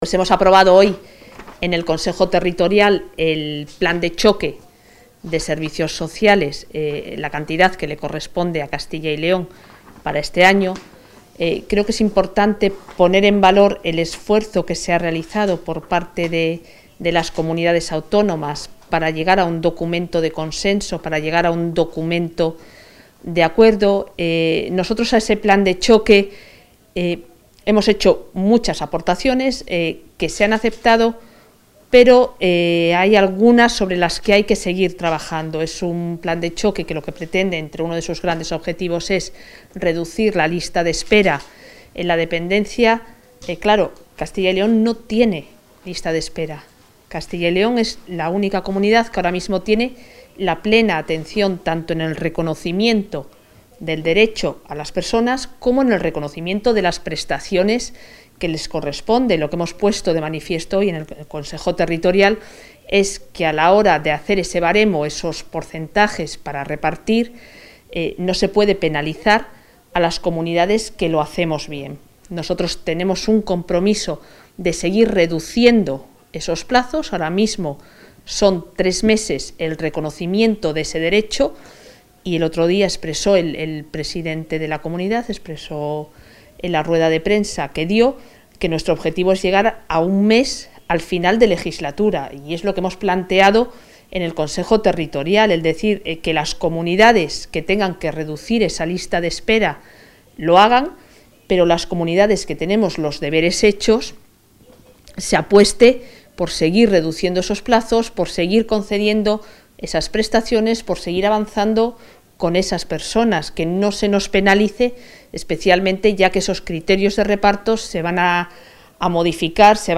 Valoración de la consejera de Familia e Igualdad de Oportunidades.